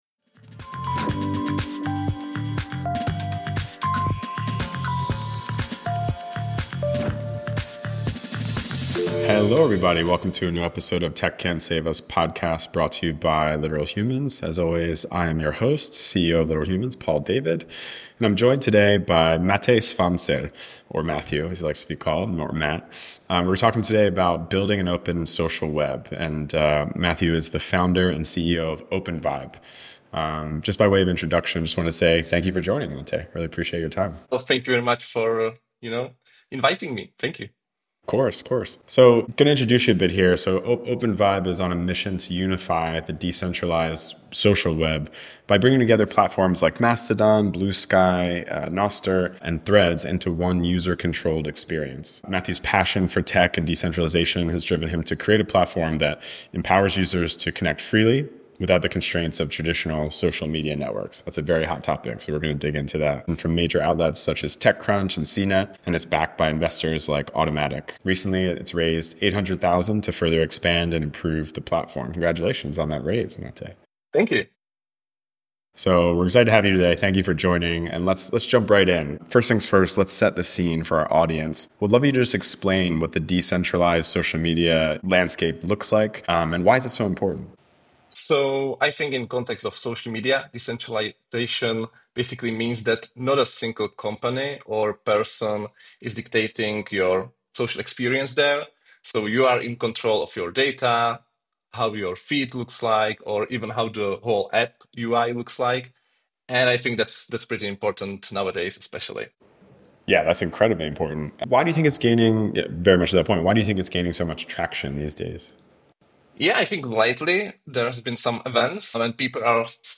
The conversation covers Openvibe's impressive growth to 80,000 downloads, the challenges of making complex decentralised tech accessible to everyday users, and how the company is using its recent $800,000 funding round to expand.